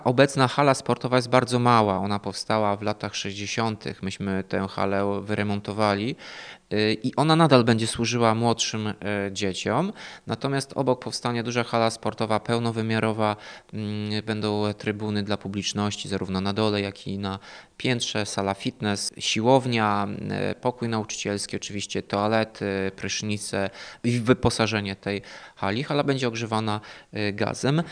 – Obok powstanie nowoczesny obiekt – mówi Tomasz Andrukiewicz, prezydent Ełku.